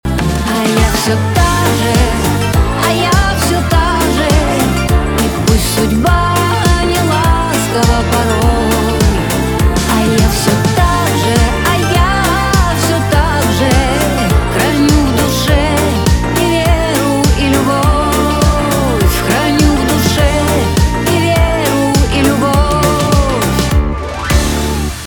эстрада
битовые